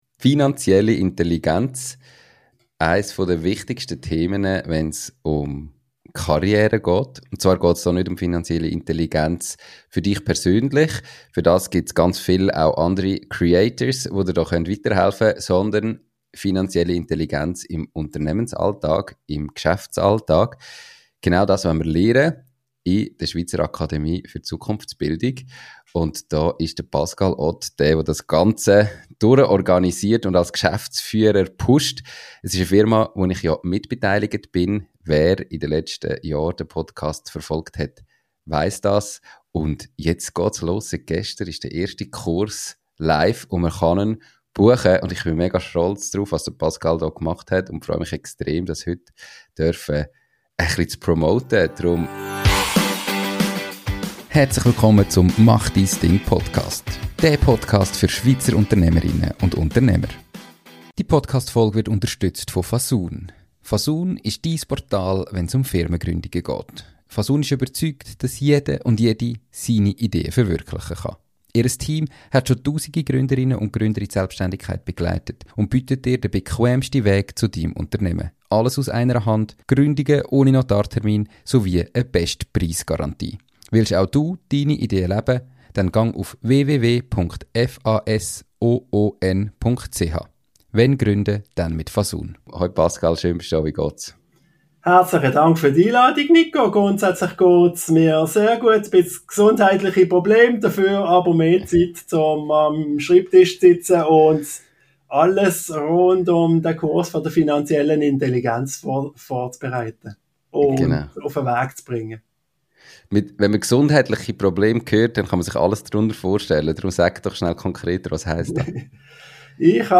Der Podcast für Schweizer Unternehmer, Selbstständige und alle, die es noch werden wollen! Erfolgreiche Unternehmer und Selbstständige aus der Schweiz erzählen dir ihre Geschichte.